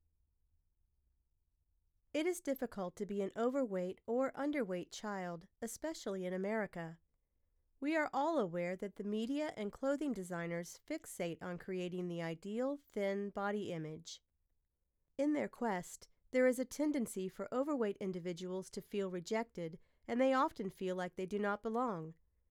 I’m using a RODE NT-1 mic with it’s custom shock mount/pop filter, running it through a Focusrite Scarlett Solo (but I have no idea what to turn the Gain knob to for the optimal input).
Now I’ve been awarded my first audiobook on ACX, and I have run the ACX Check on the attached RAW sample: